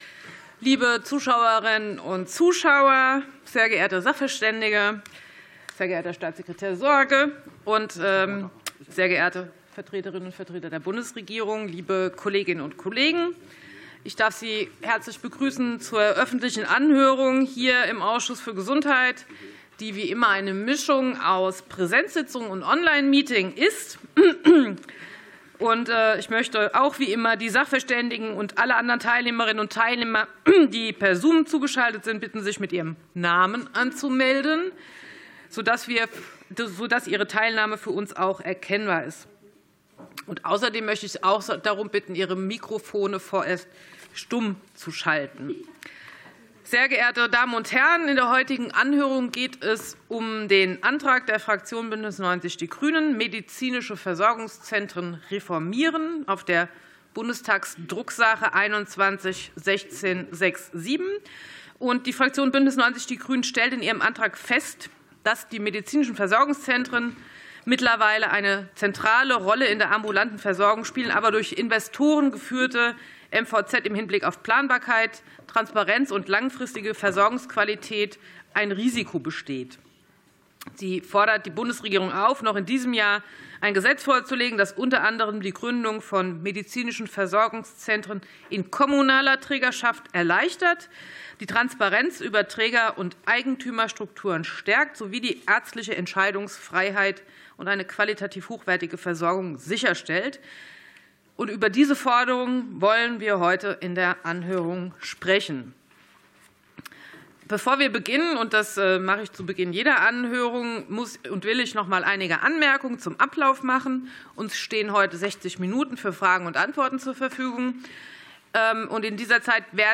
Anhörung des Ausschusses für Gesundheit